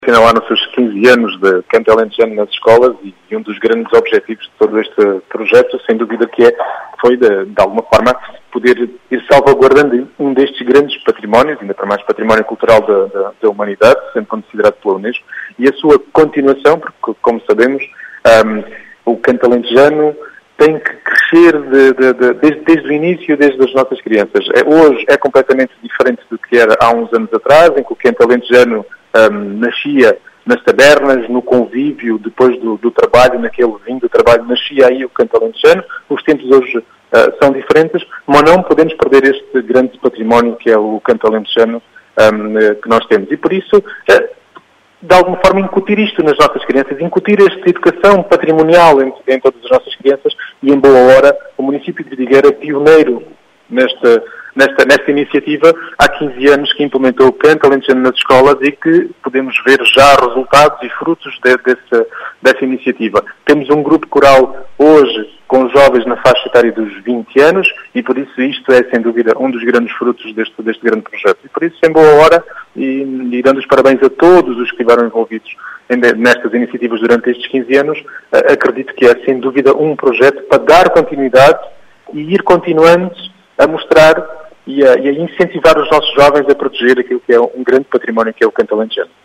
As explicações são do presidente da Câmara Municipal de Vidigueira, Rui Raposo, que relembra a “salvaguarda” deste património como o grande objectivo.